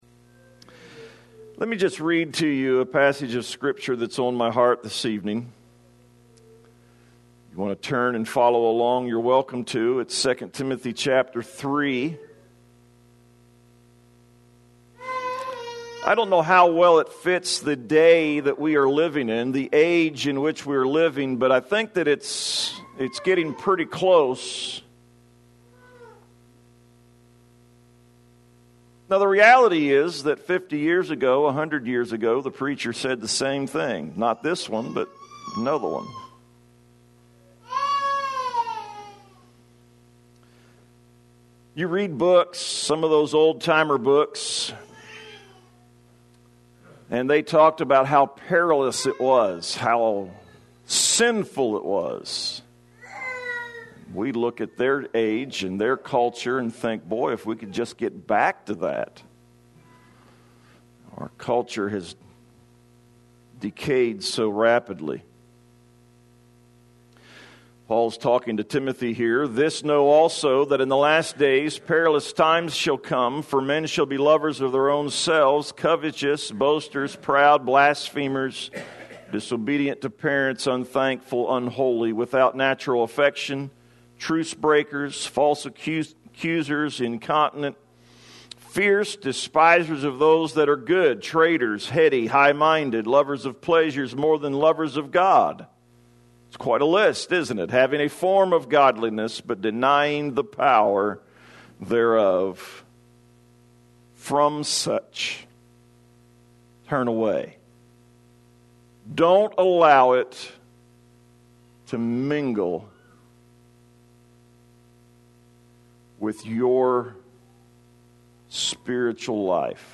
A short sermon